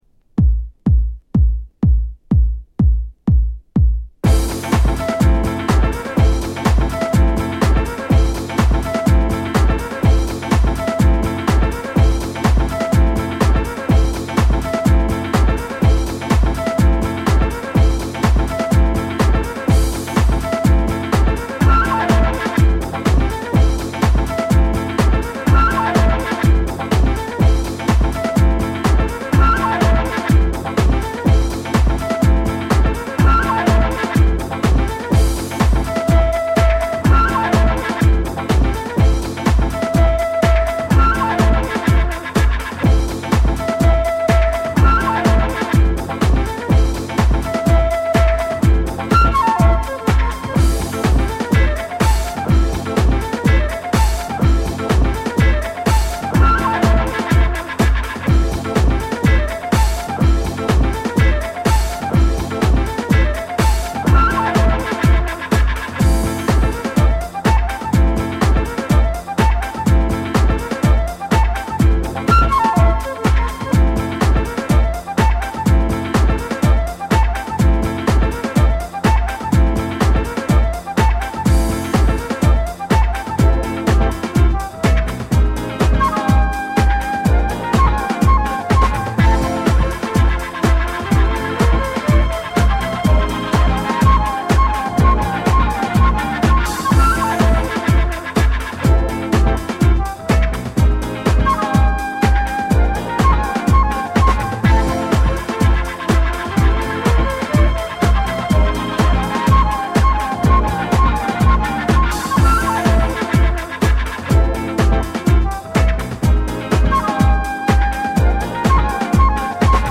Disco , House , Rare Groove , Re-Edit